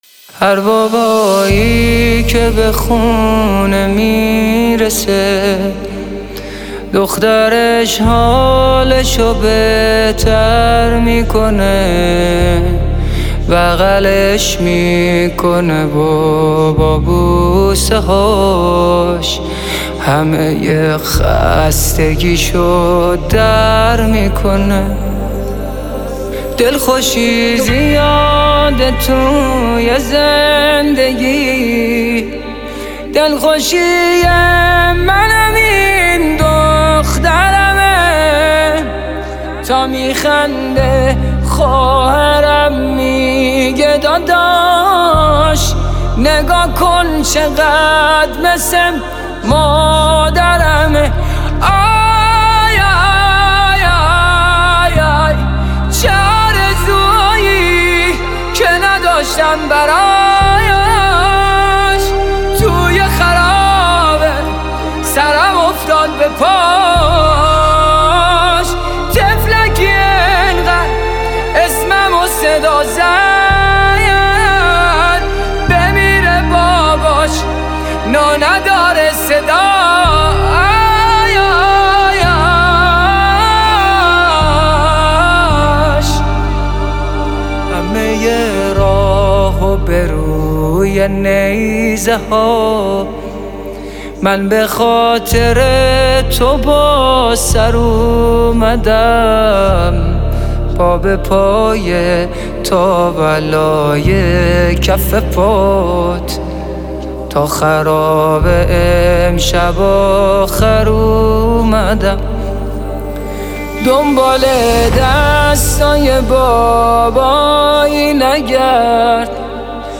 نماهنگ
حسینیه کربلا اندرزگو تهران